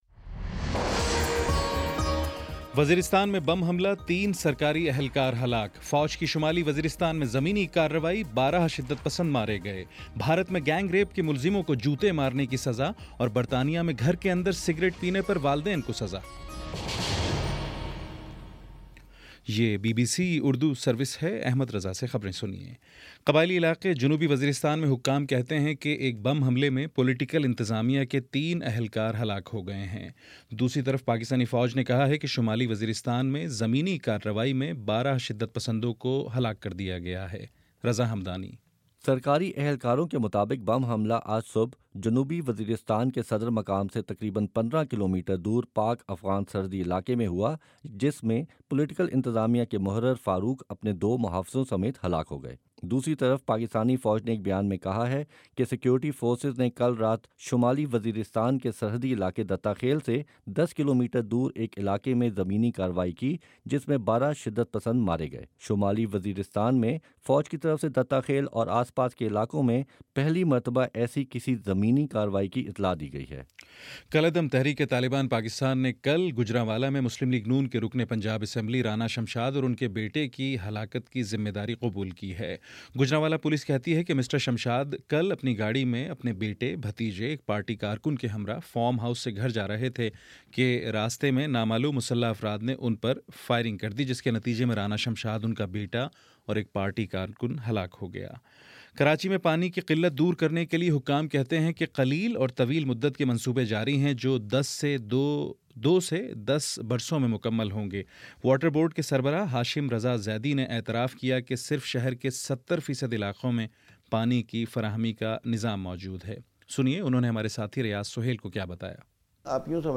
جون 1: شام سات بجے کا نیوز بُلیٹن